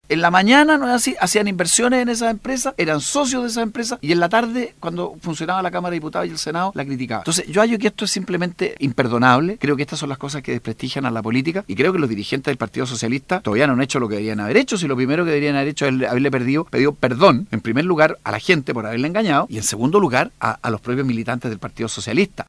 Cómo la gente no se va a sentir profundamente decepcionada fueron las palabras emitidas por Andrés Allamand, al conversar con radio Sago sobre las polémicas inversiones del partido Socialista durante su visita a Osorno.